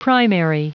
Prononciation du mot primary en anglais (fichier audio)
Prononciation du mot : primary